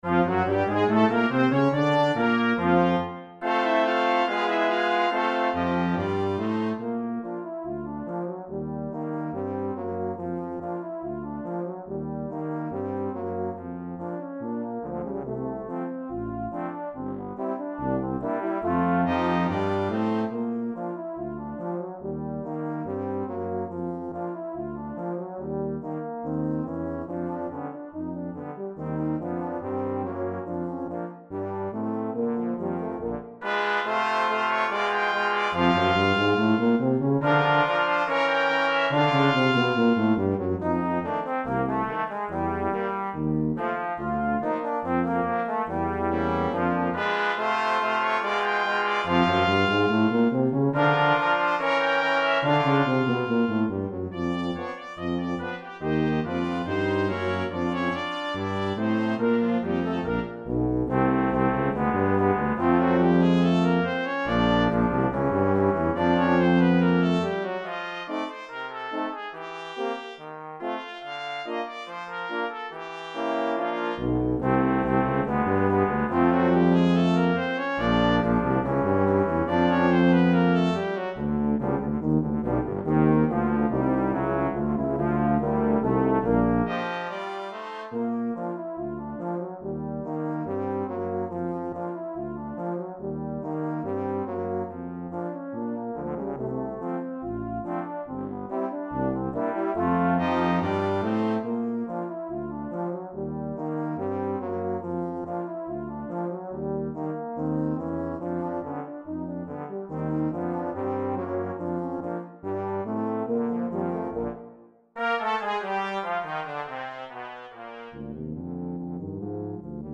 3 Trumpets
2 Horns in F
2 Trombones
Euphonium
Tuba
for Brass Nonet